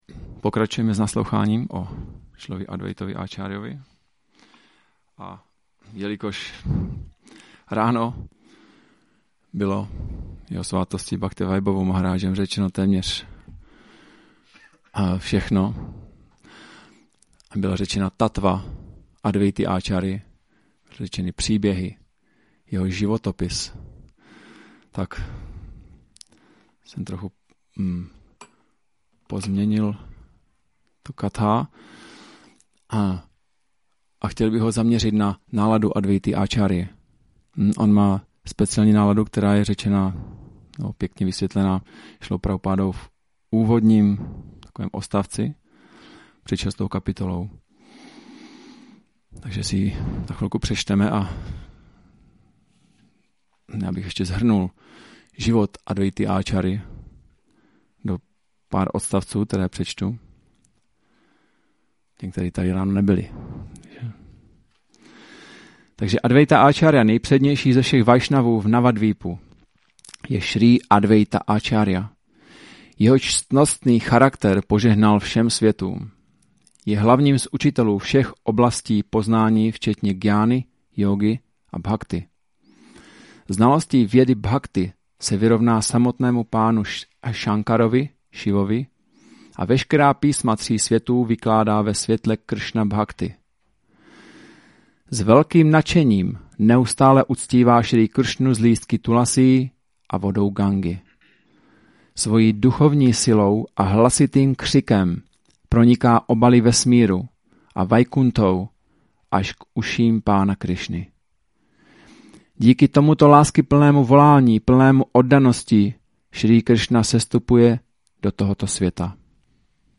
Přednáška Advaita acarya katha